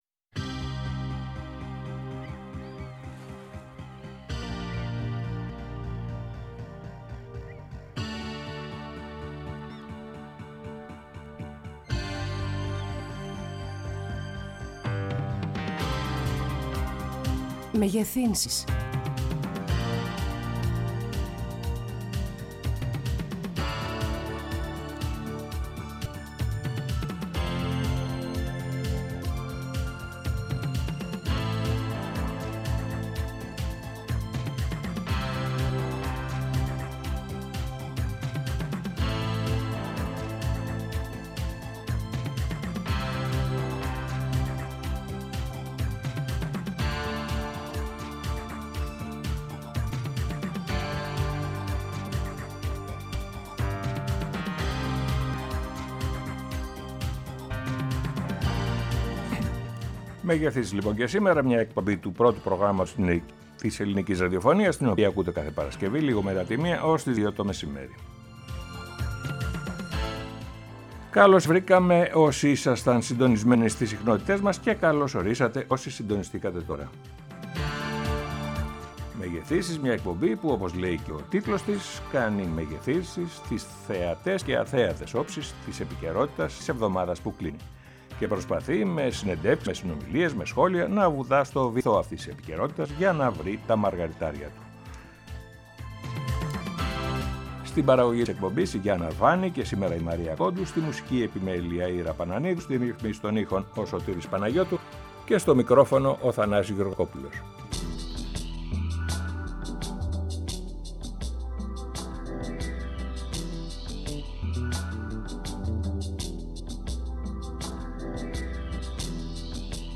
Μια εκπομπή η οποία κάνει μεγεθύνσεις στις θεατές και αθέατες όψεις της επικαιρότητας, της βδομάδας που κλείνει και προσπαθεί με συνομιλίες με ανθρώπους που -κατά τεκμήριο- γνωρίζουν και με σχολιασμό να βουτά στο …. βυθό αυτής της επικαιρότητας για να βρει τα ….. μαργαριτάρια του.